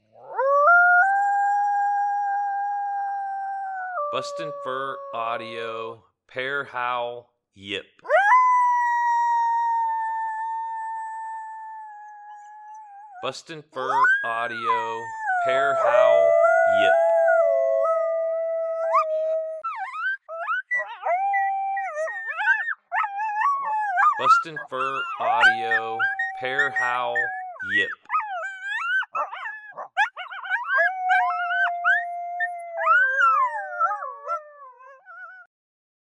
BFA Pair Howl Yip
BFA's Cash and Apollo pair howling together. As this howl progresses, excitement builds.
BFA Pair Howl Yip Sample.mp3